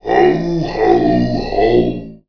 hohoho.wav